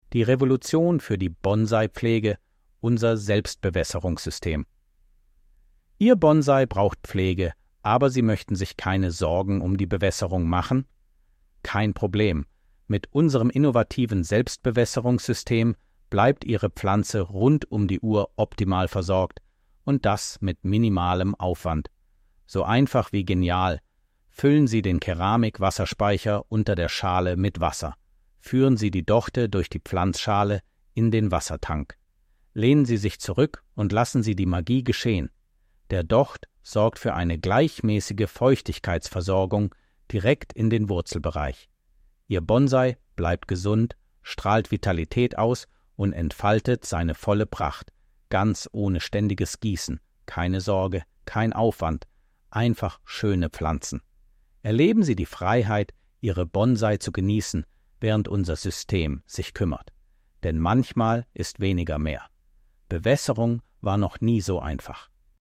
Audio-Datei mit vorgelesenem Text über die Gärtnerei Hohn.